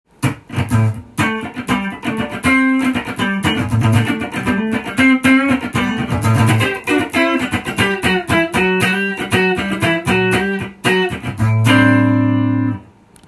ピックアップマイクも５０年前のオリジナルのものなので磁力が弱く　枯れた感じがします。
最近のピックアップマイクとは違う何とも言えない切ない音がしました。